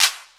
35CLAP01  -R.wav